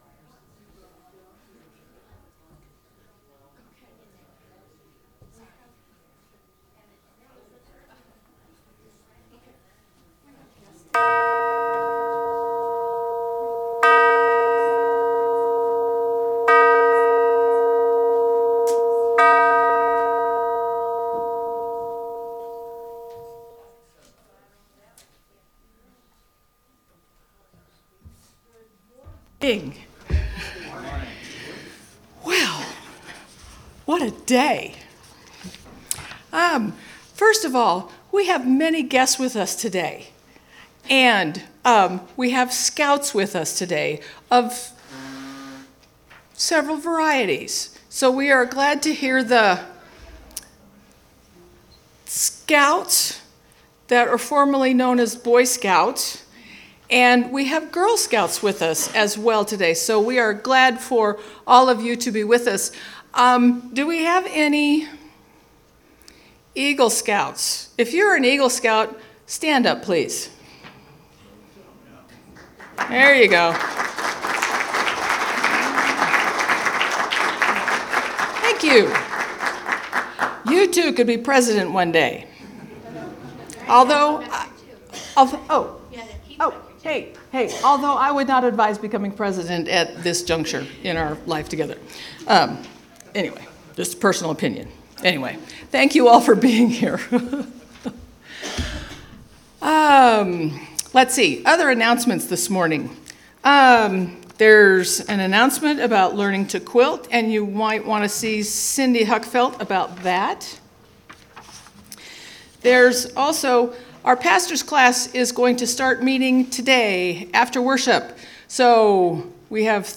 If you are unable to attend the service feel free to listen to it on-line.